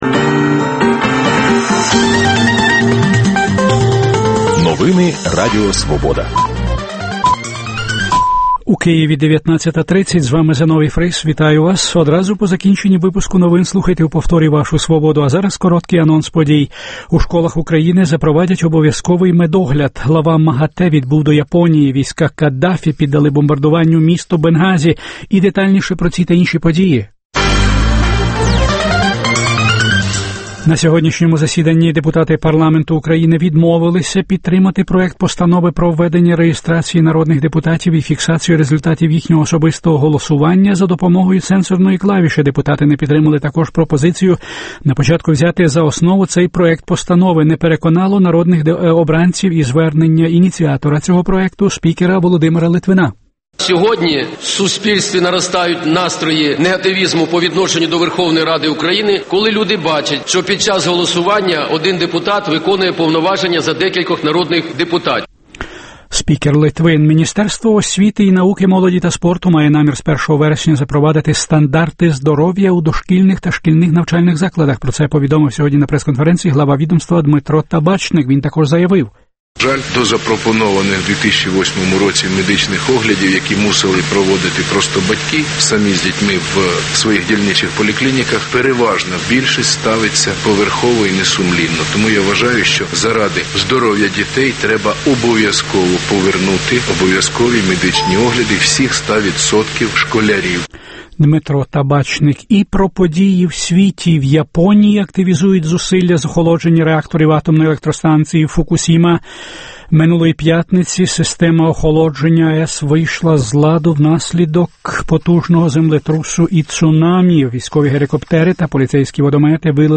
Дискусія про головну подію дня.